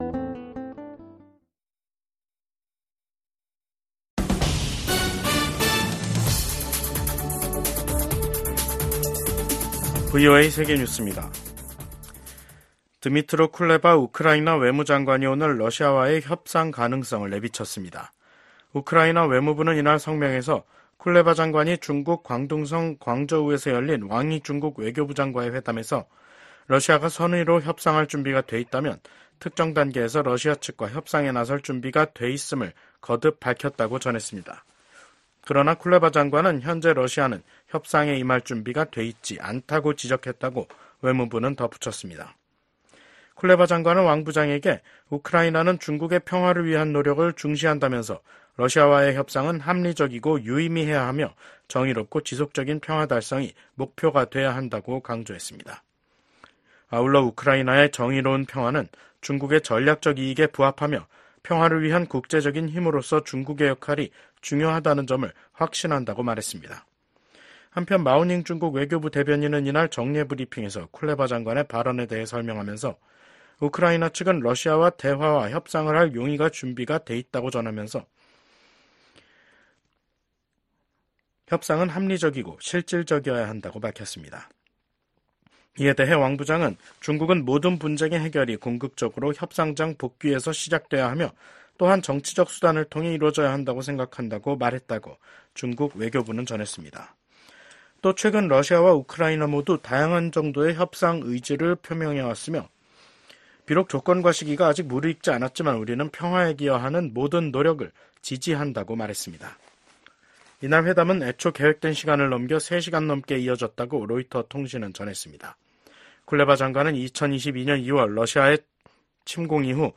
VOA 한국어 간판 뉴스 프로그램 '뉴스 투데이', 2024년 7월 24일 2부 방송입니다. 북한이 또 다시 쓰레기 풍선을 한국 쪽에 날려보냈습니다.